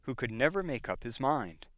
Exercise 3G Sounds spoken by:
American speaker